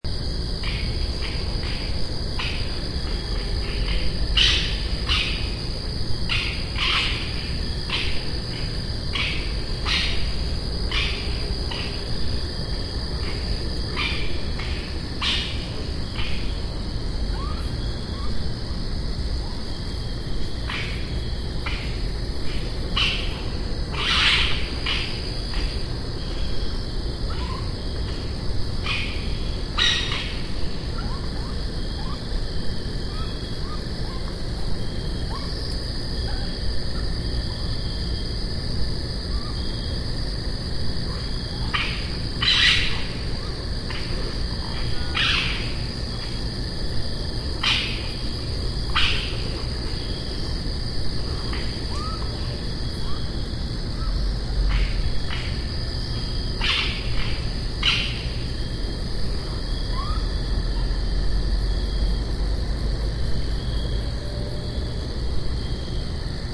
Animal Suburban Woodlot Night Bouton sonore
Animal Sounds Soundboard1,731 views